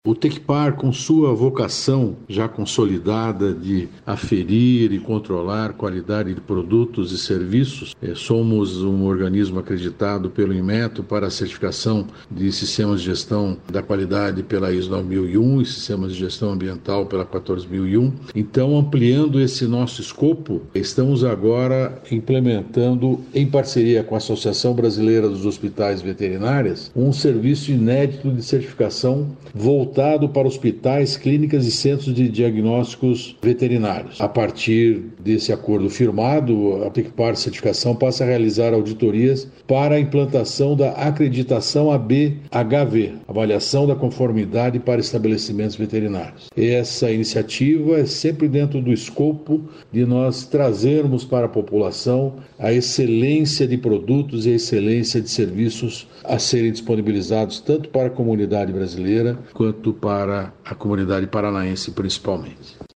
Sonora do diretor-presidente do Tecpar, Celso Kloss, sobre a parceria com a ABHV para certificar hospitais, clínicas e centros veterinários